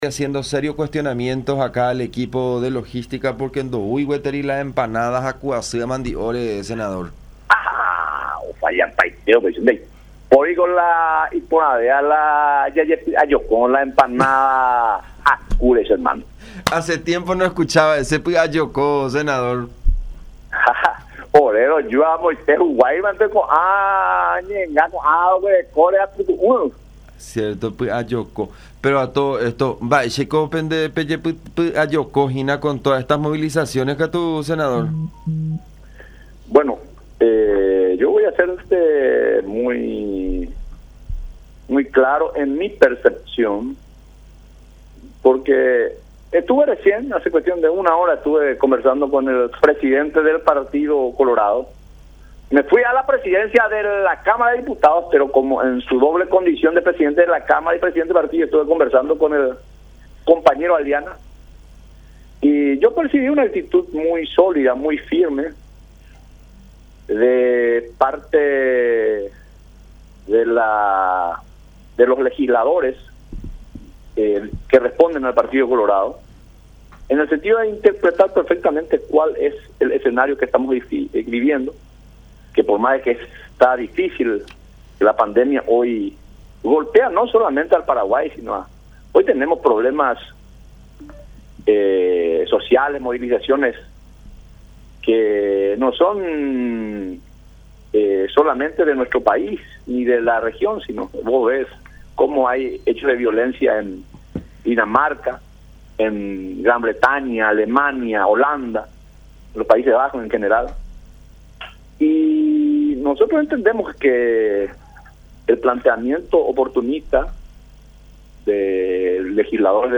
Entendemos que el planteamiento oportunista no va a correr”, declaró Ovelar en diálogo con La Unión.